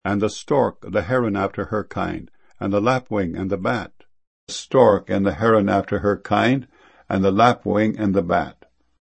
bat.mp3